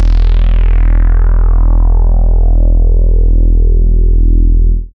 75.04 BASS.wav